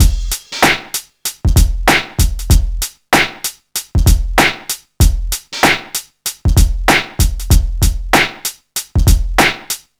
Free breakbeat - kick tuned to the F note. Loudest frequency: 1790Hz
• 96 Bpm Drum Beat F Key.wav
96-bpm-drum-beat-f-key-Vdz.wav